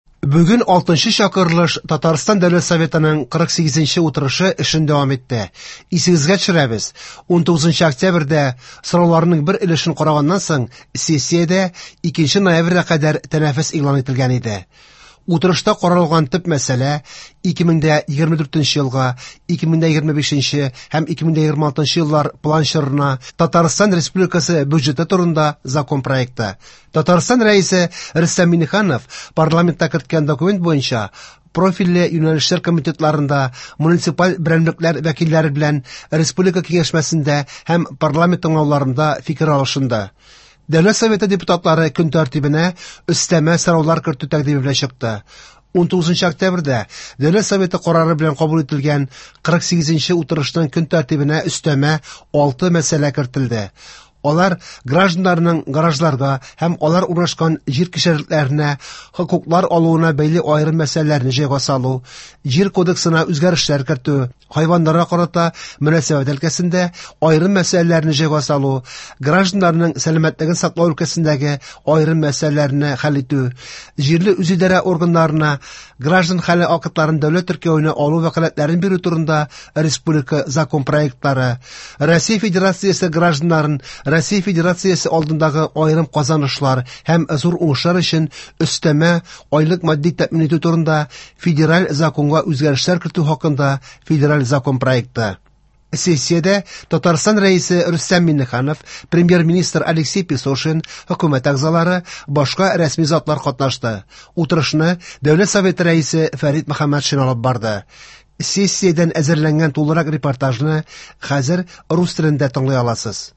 Радиоотчет (02.11.23) | Вести Татарстан